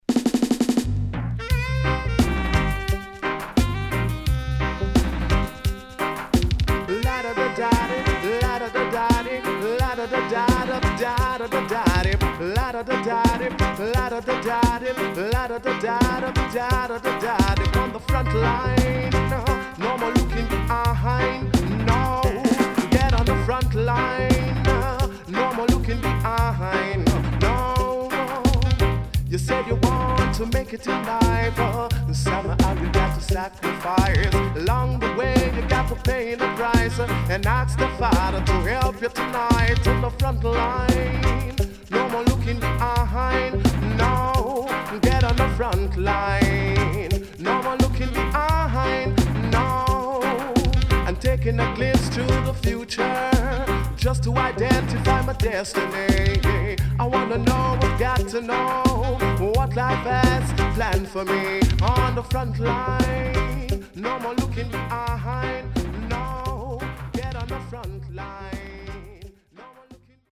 HOME > Back Order [DANCEHALL LP]
SIDE A:所々チリノイズがあり、少しプチパチノイズ入ります。